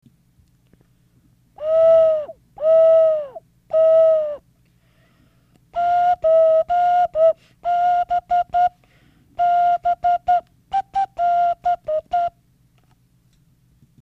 試聴　後半は、指穴を2箇所あけたホーホー笛を吹いています